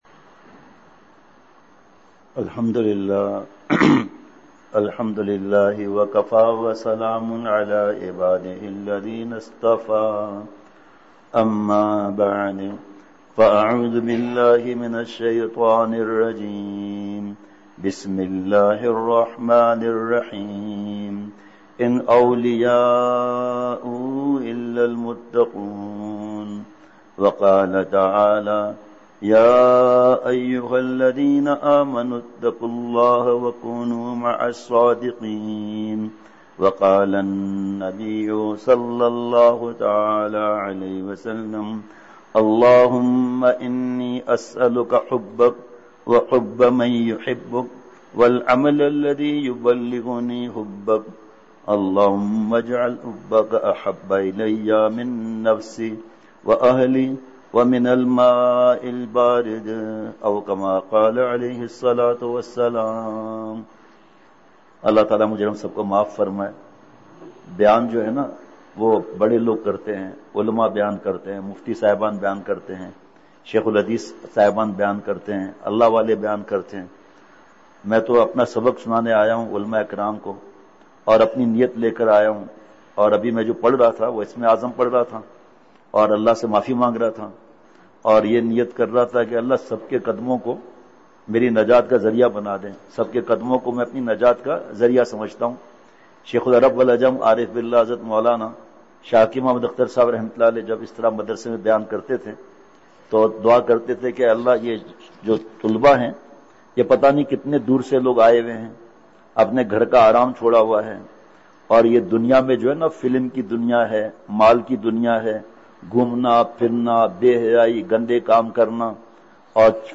اصلاحی مجلس
بمقام: جامعہ معراج العلوم چمن